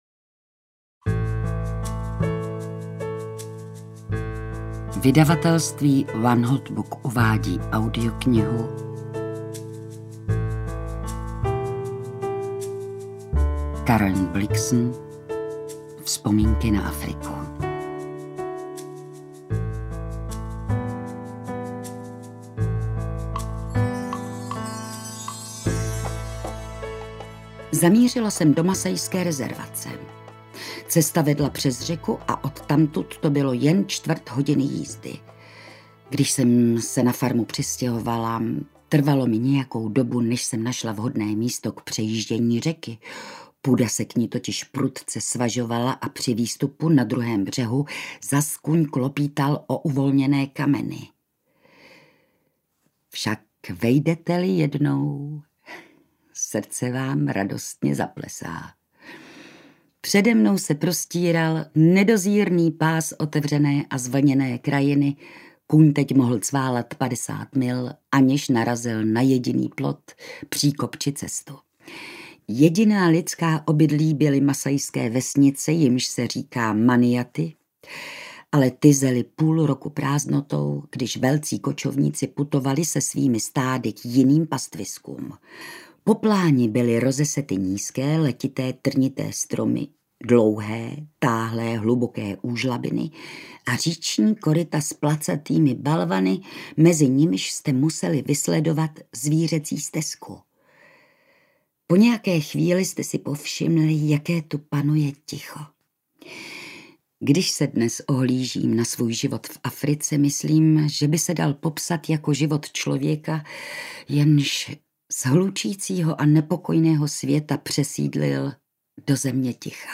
Vzpomínky na Afriku audiokniha
Ukázka z knihy